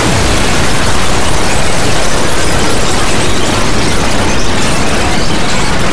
ALIENNOISE-L.wav